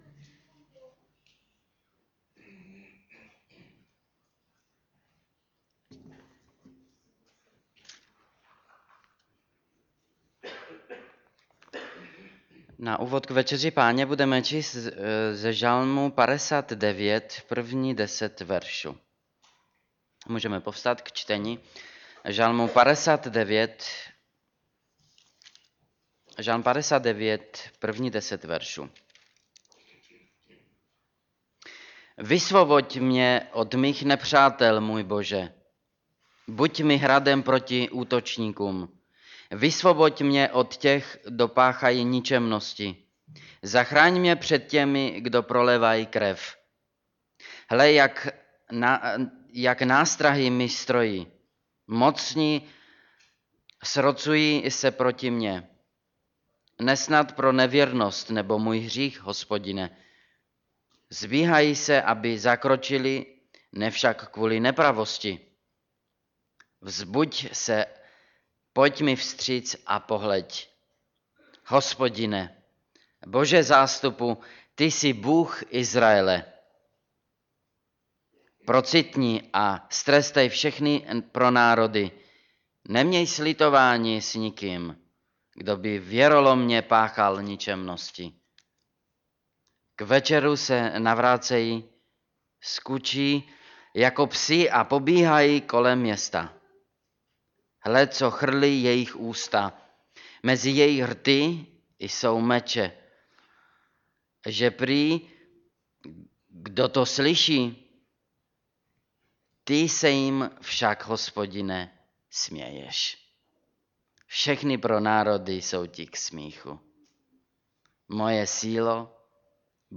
Bůh se směje Kategorie: Kázání MP3 Zobrazení: 3210 Boží ironie (zamyšlení k Večeří Páně) Předchozí článek: Důvody k přijeti Večeře Páně Předchozí Další článek: Umení pro harmonické manželství -2. část Následující